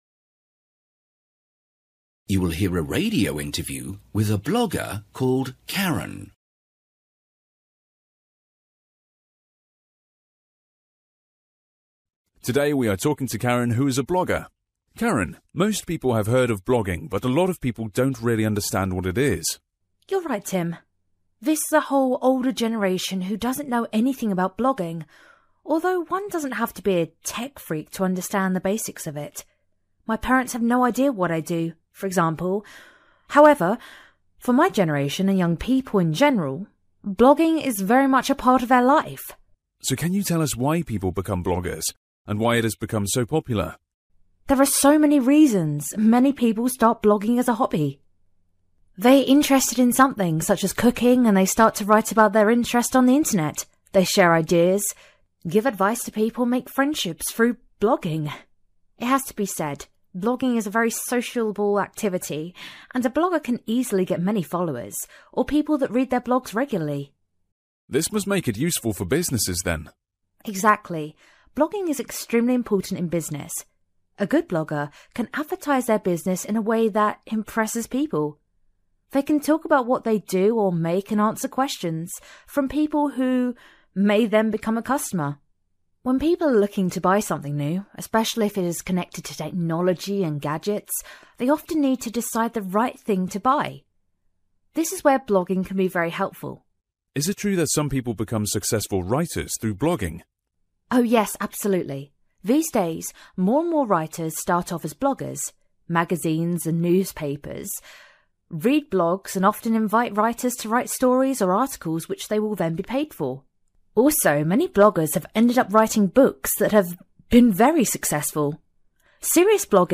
Listening: a blogger